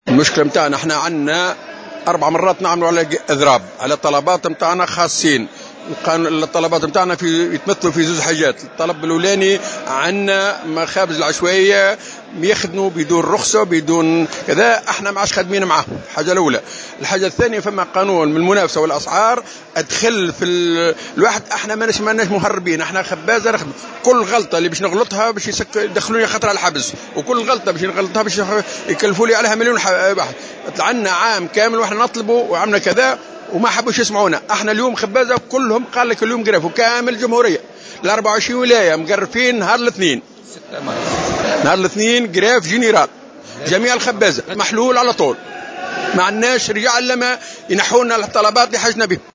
Les propriétaires de boulangeries ont effectué ce jeudi 2 mars 2017 une manifestation devant le siège de l’UTICA, exigeant la structuration du secteur vu le nombre élevé de boulangeries illégales et la revue de certaines des procédures relatives à la loi relative à la concurrence et aux prix ainsi que les taux des infractions. Intervenu sur les ondes de Jawhara FM le 2 mars 2017